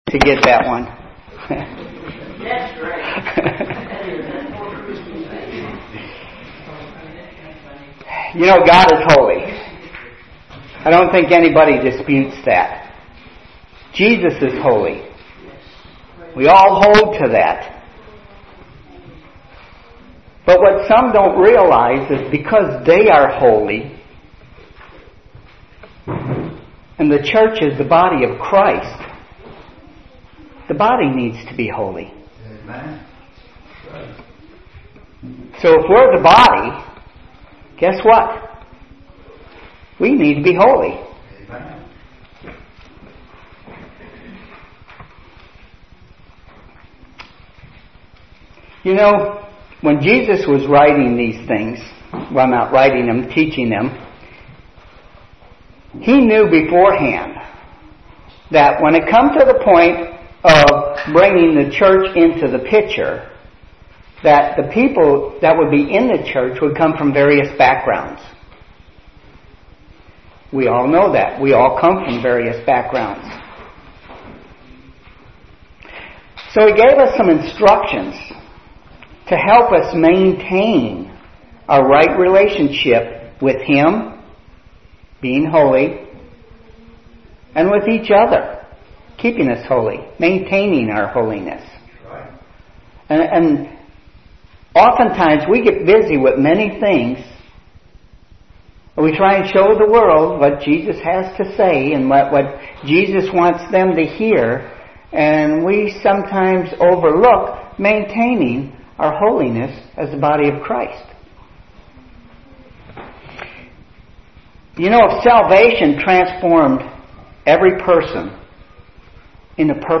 Sermons |